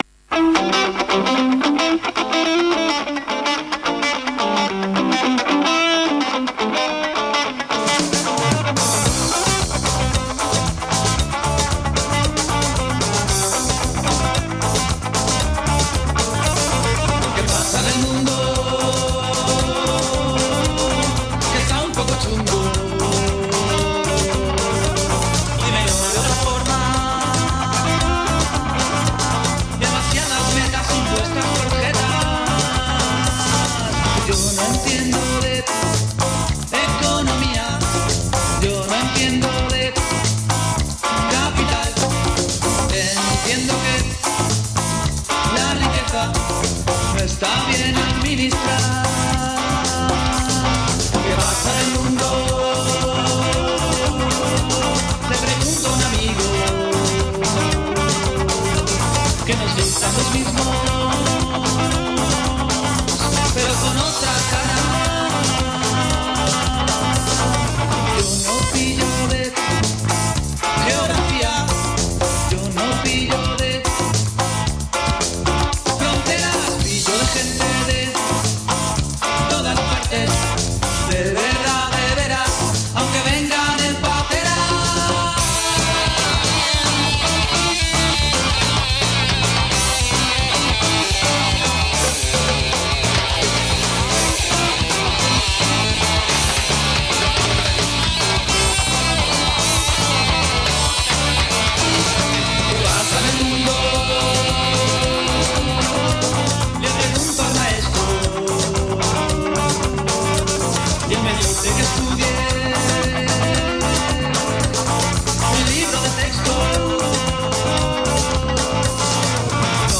Llamada telefónica comentando los últimos sucesos en Siria.